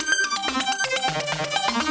Index of /musicradar/shimmer-and-sparkle-samples/125bpm
SaS_Arp03_125-C.wav